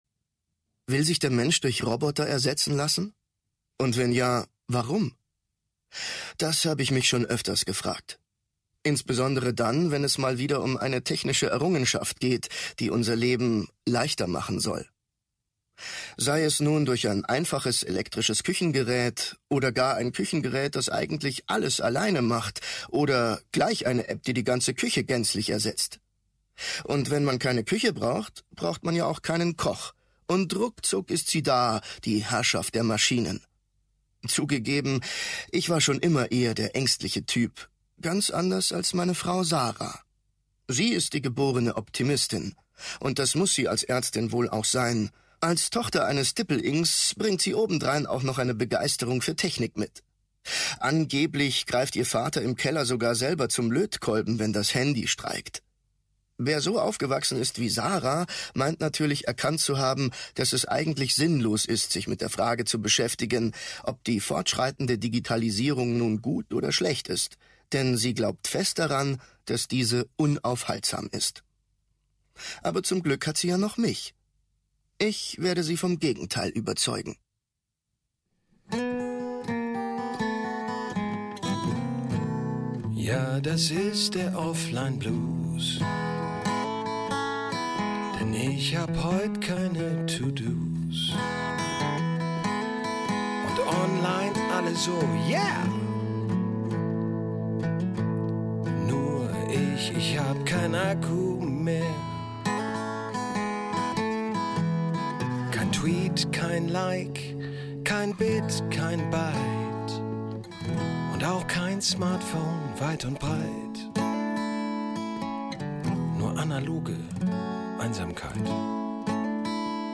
Hörbuch: Als Schisser durchs Netz.
Als Schisser durchs Netz. Eine Berg- und Digitalfahrt der Gefühle Ungekürzte Lesung